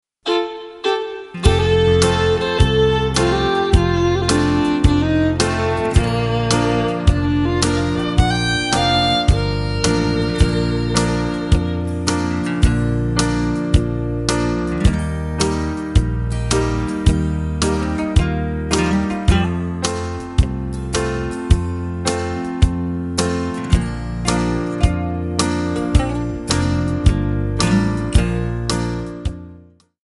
Bb/C
MPEG 1 Layer 3 (Stereo)
Backing track Karaoke
Country, Duets, 1990s